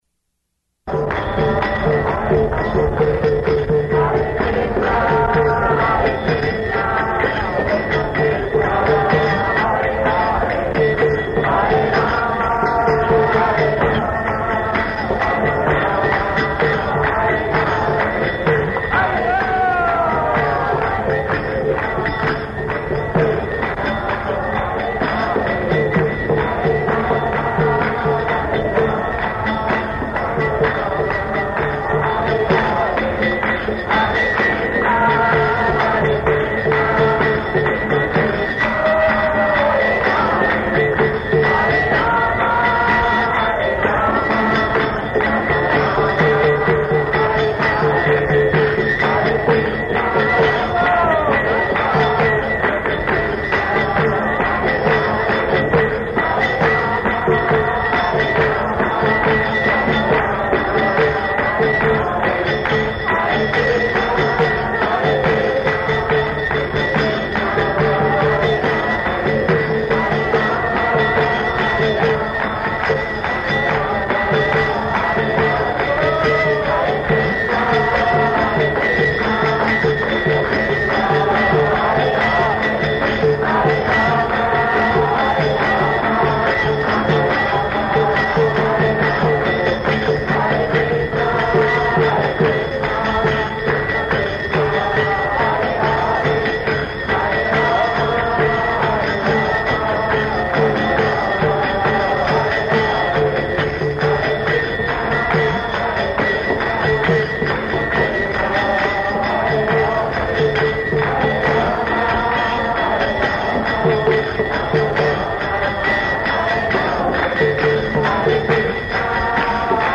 Location: Jakarta
[ Kīrtana ] [poor audio]